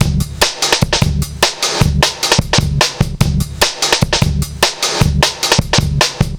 Index of /90_sSampleCDs/Zero-G - Total Drum Bass/Drumloops - 1/track 01 (150bpm)